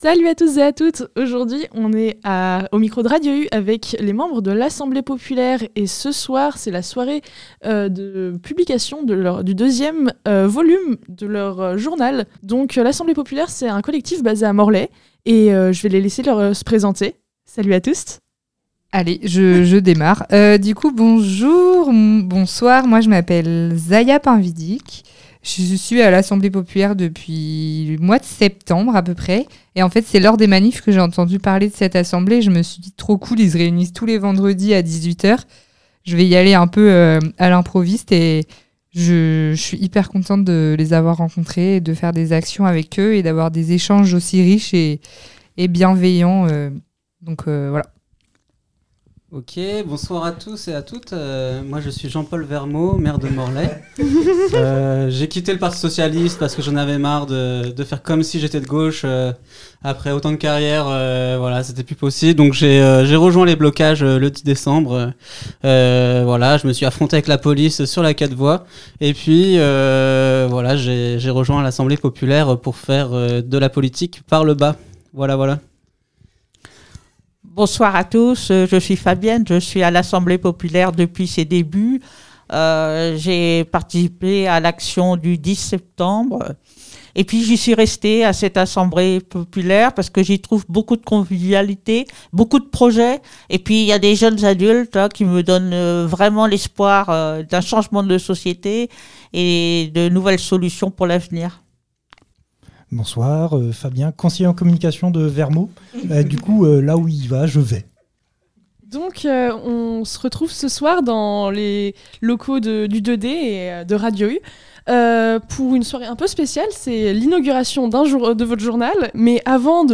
On en parle avec le collectif qui présente au micro le deuxième numéro de leur journal intitulé "Ça presse !".
itw_assemblee_populaire_montee.mp3